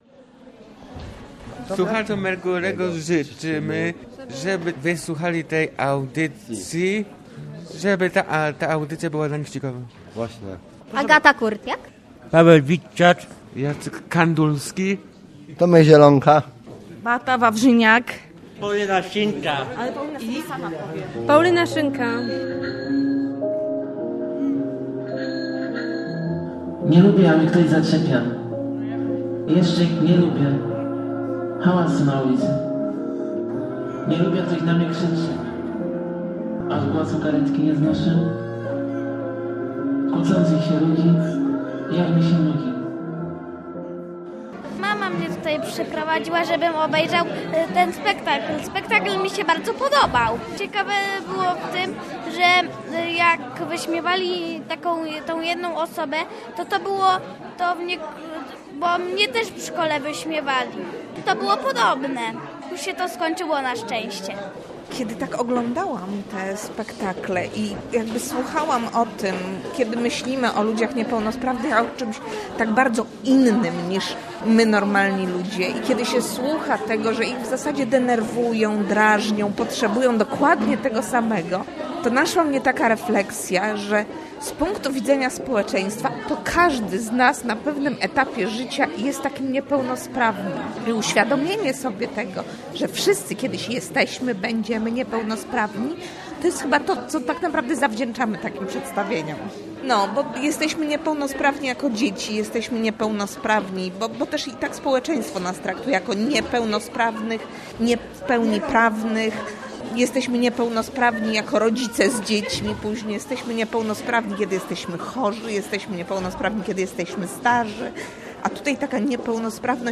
Niepełnosprawni w teatrze - reportaż